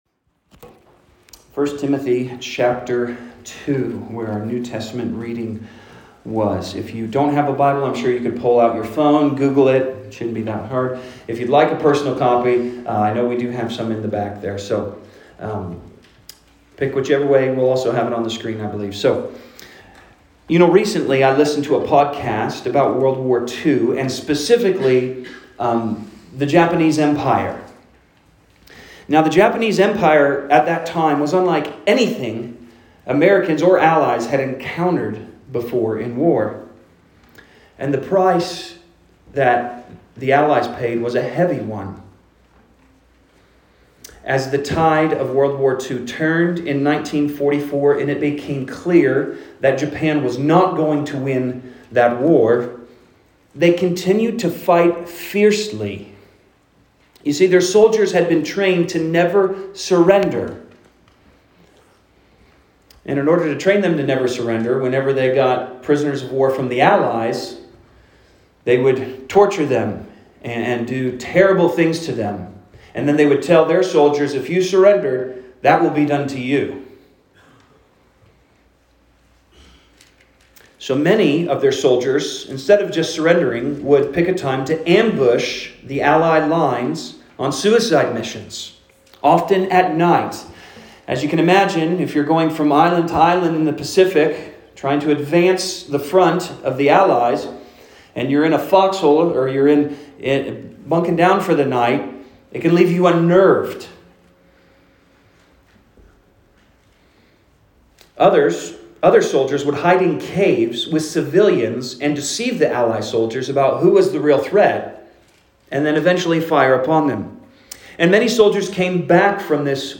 Sermons | Grace Gospel Church